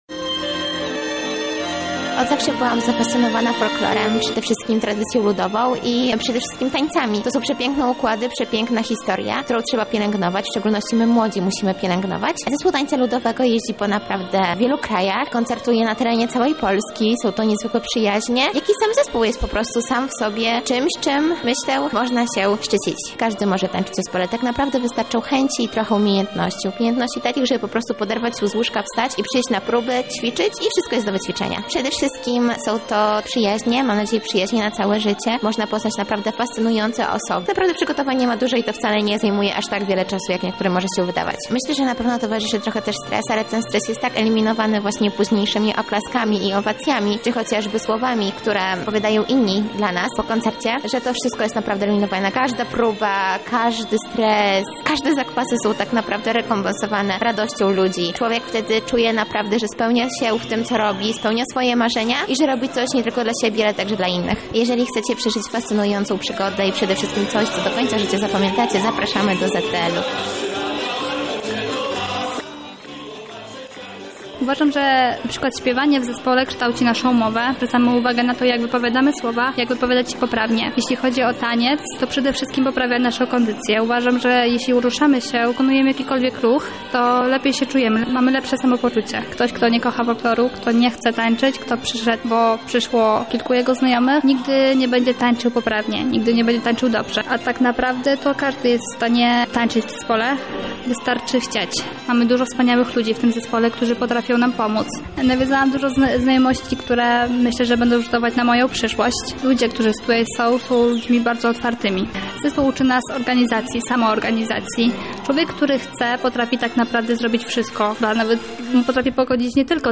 taniec zespol ludowy (obrazek)_mixdown